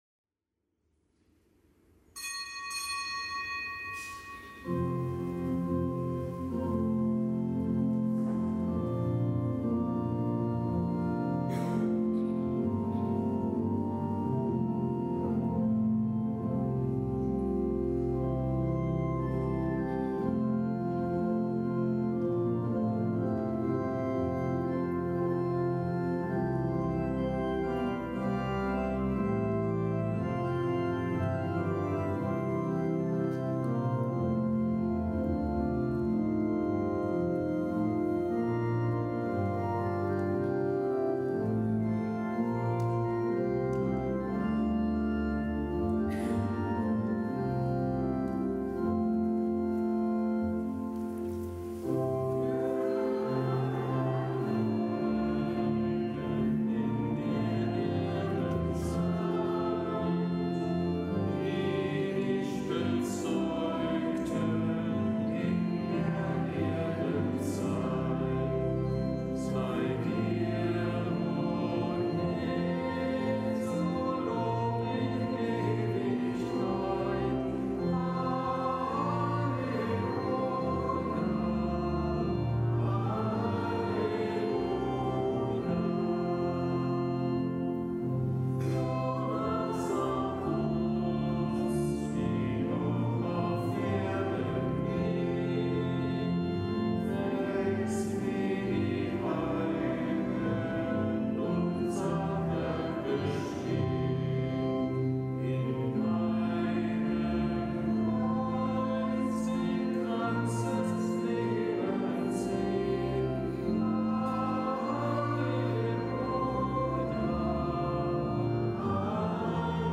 Kapitelsmesse am Gedenktag Heiliger Kornelius und heiliger Cyprian
Kapitelsmesse aus dem Kölner Dom am Gedenktag Heiliger Kornelius, Papst, und heiliger Cyprian, Bischof von Karthago, Märtyrer